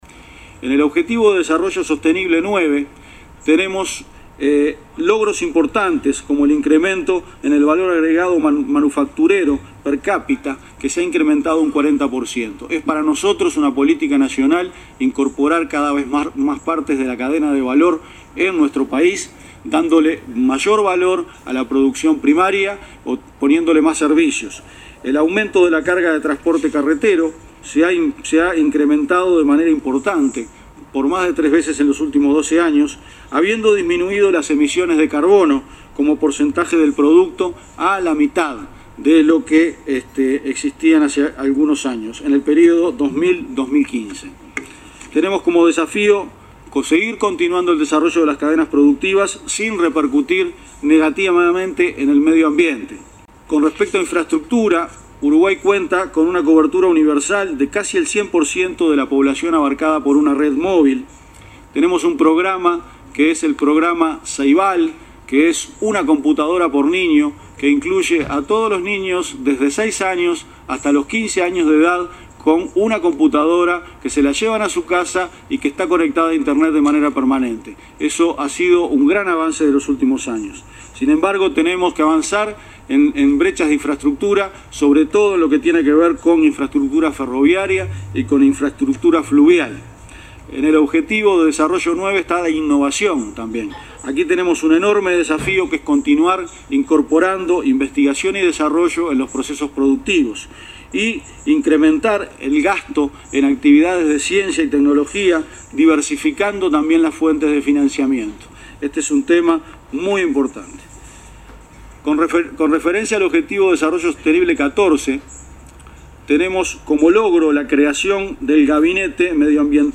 El director de OPP, Álvaro García, disertó en el foro para los Objetivos de Desarrollo Sostenible de Naciones Unidas, en Nueva York.